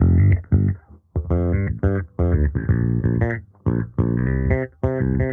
Index of /musicradar/sampled-funk-soul-samples/90bpm/Bass
SSF_JBassProc1_90G.wav